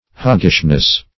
-- Hog"gish*ness, n.